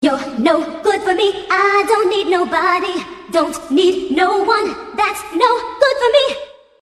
Главная » Файлы » Акапеллы » Скачать Фразы и Произношения
Категория: Скачать Фразы и Произношения